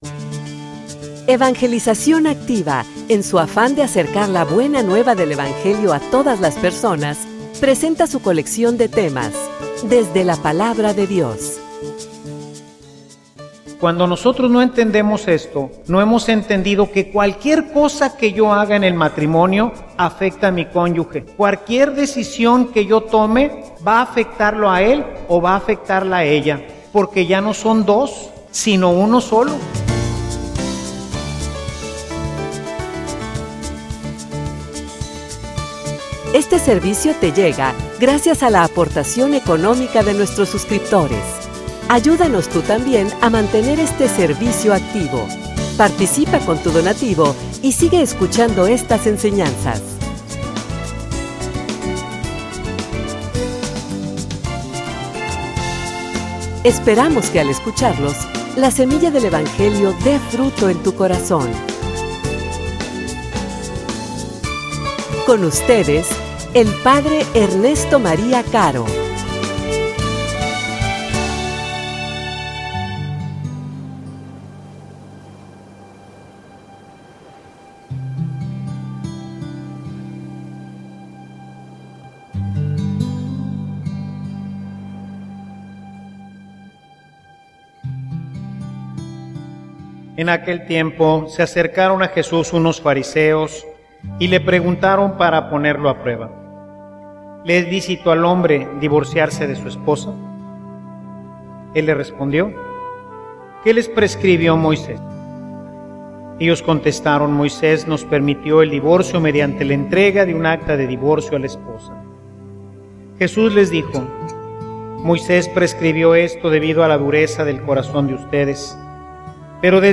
homilia_Seran_una_sola_carne.mp3